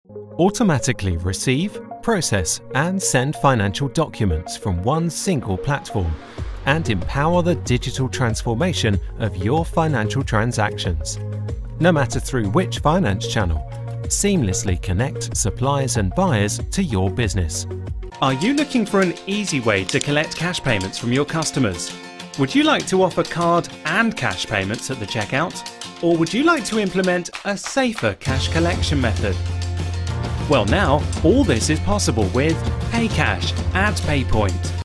English (British)
Natural, Friendly, Corporate, Commercial, Warm
Explainer